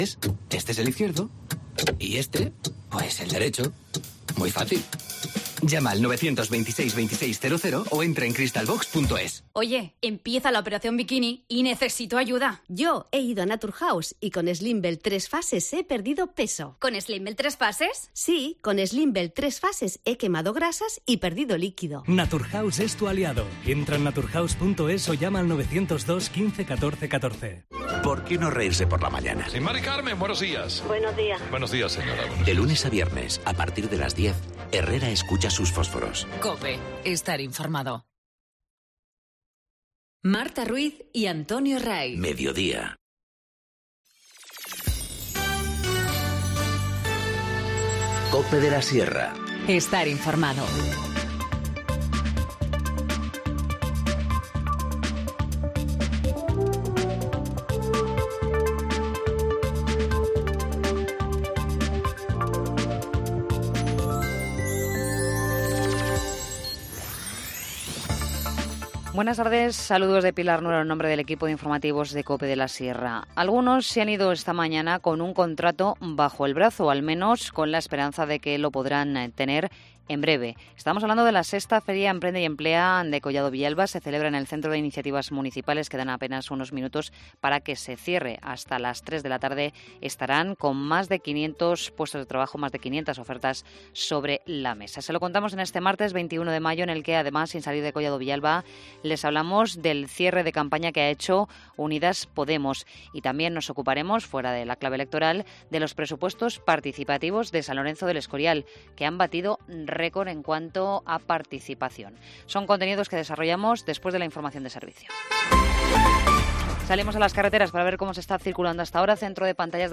Informativo Mediodía 21 mayo 14:20h